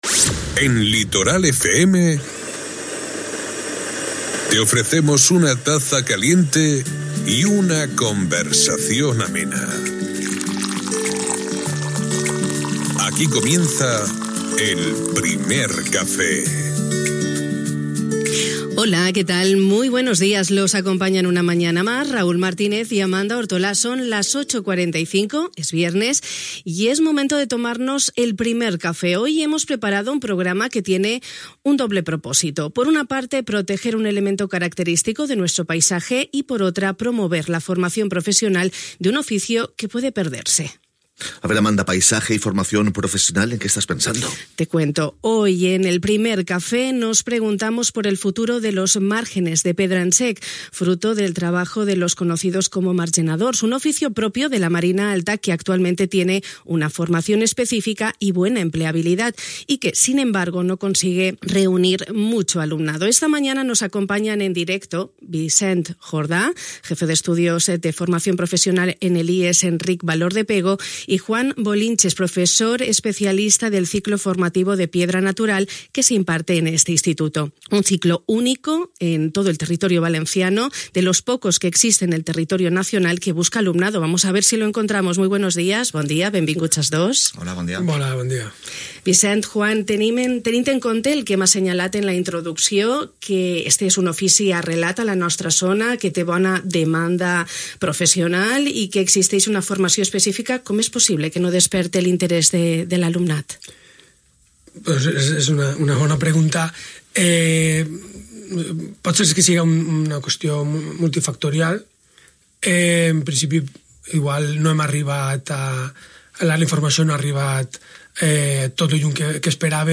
Un tema que hem abordat amb veus experts.